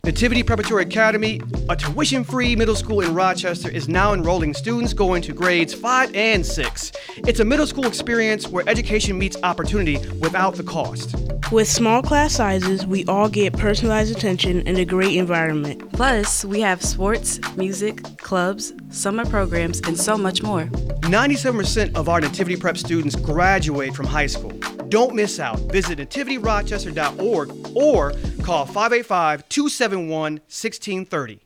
Check out our new radio ad!